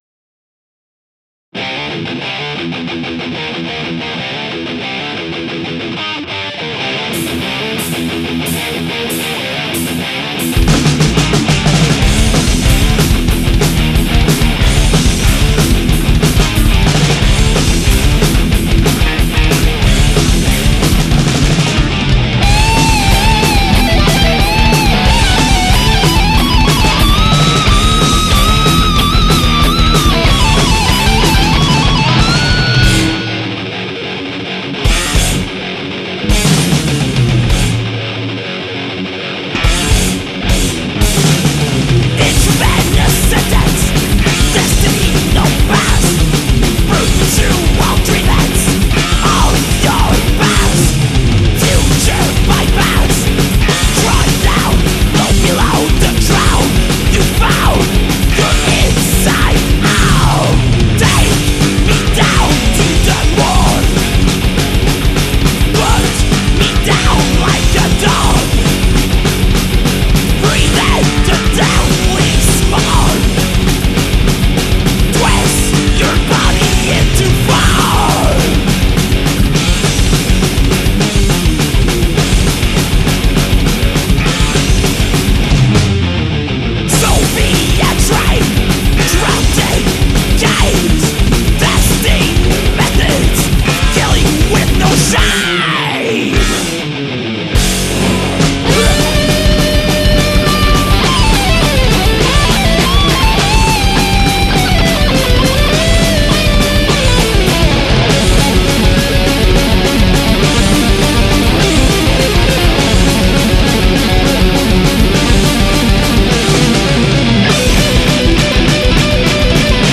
Thrash Metal
unique mix of technical, speed and thrash